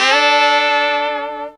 LONG HARM 2.wav